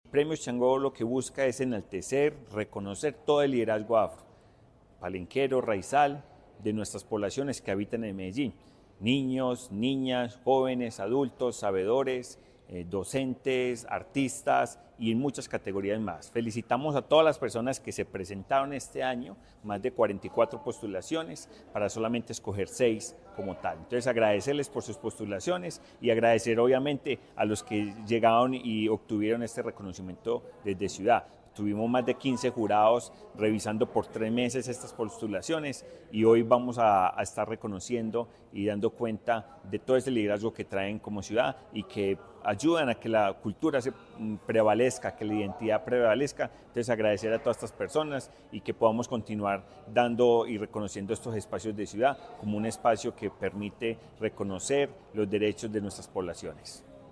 Declaraciones-del-gerente-de-Etnias-Johnatan-David-Hernandez-Serna.mp3